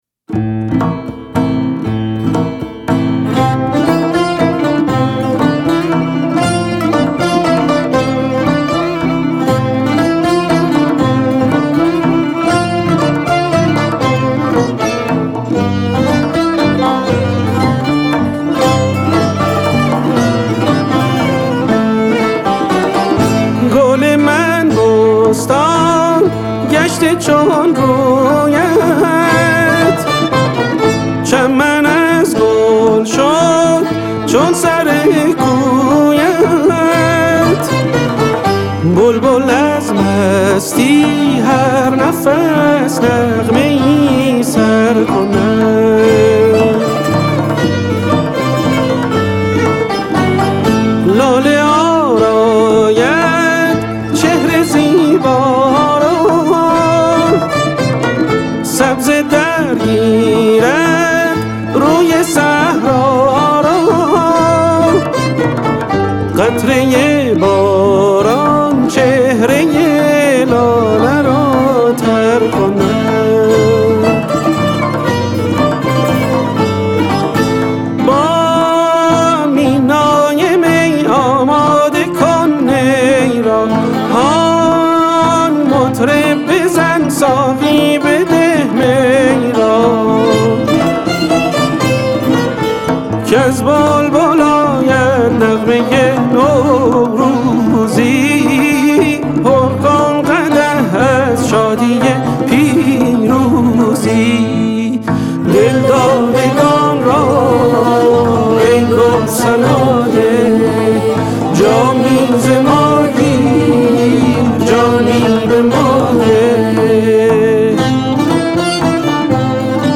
ویولن
سه‌تار